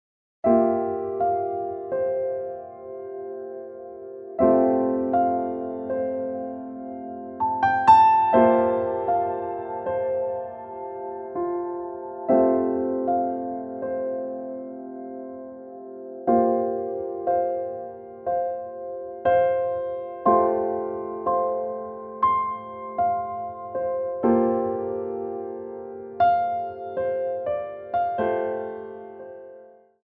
• PODKŁAD MP3 w wersji fortepianowej